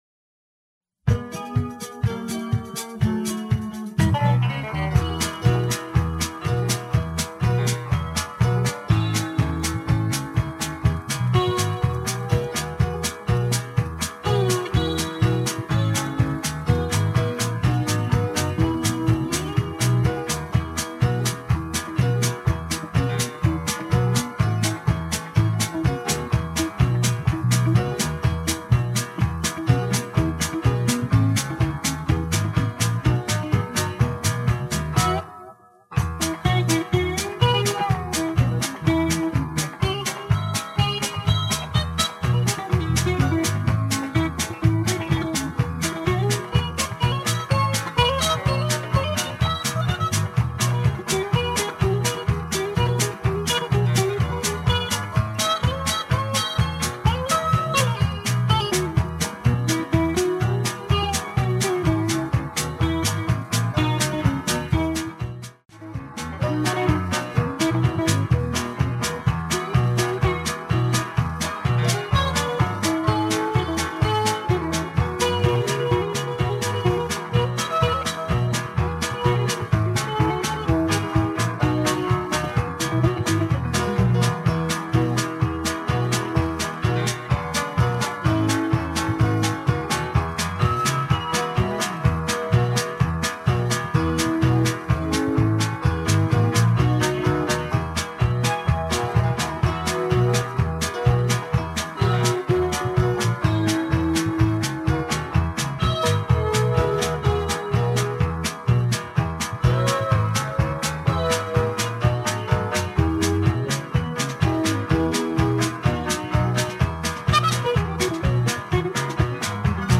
SC = Singing Call